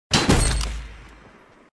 fortnite headshot Sound Button: Unblocked Meme Soundboard
Play the iconic fortnite headshot sound button for your meme soundboard!